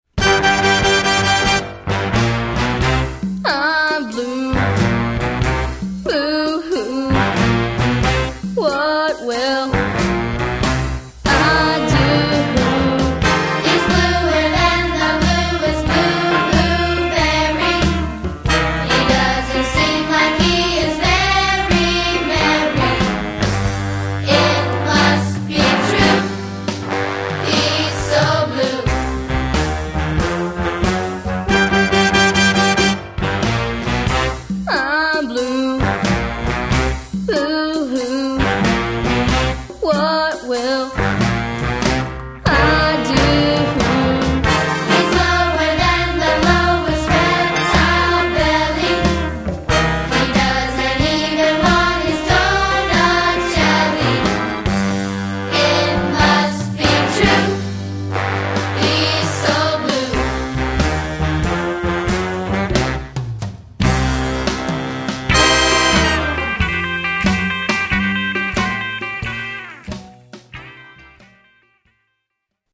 A Rockin’ Holiday Fantasy For Young Voices